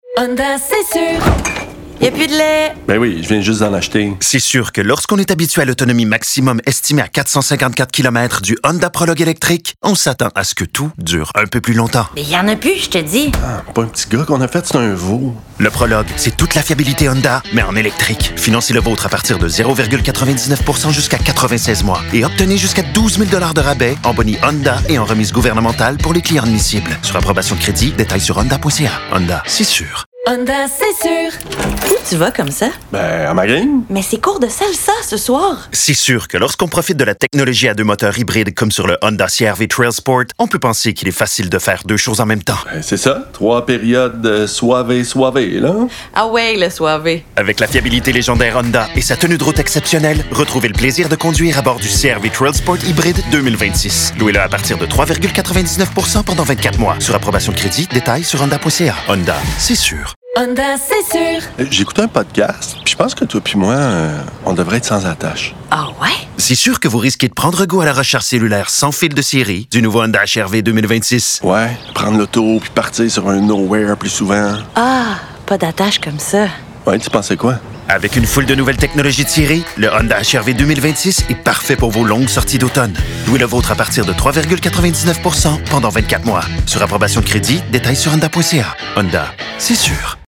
Voix de personnage – Honda
jeu vrai, touche d’humour